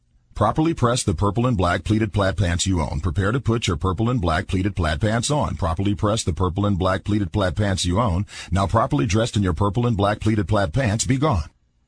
tongue_twister_06_02.mp3